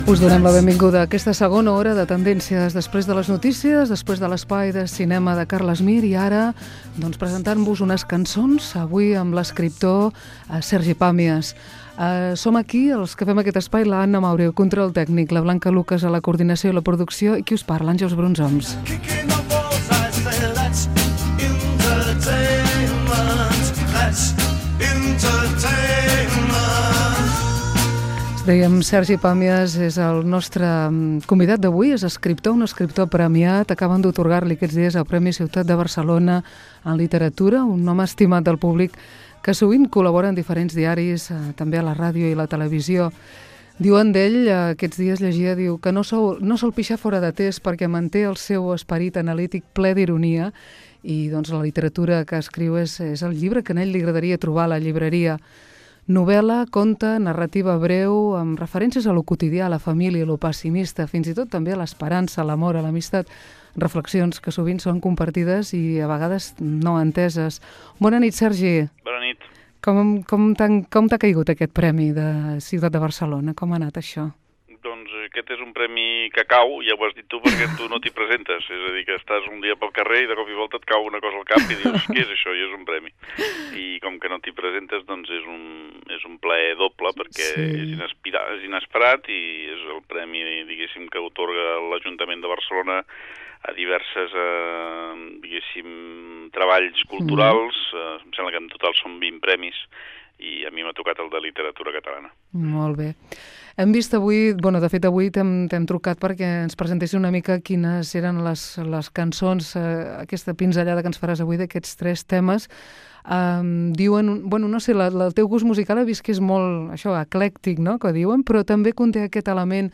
Presentació de la segona hora, equip i fragment d'una entrevista a Sergi Pàmies, premi Ciutat de Barcelona.
Entreteniment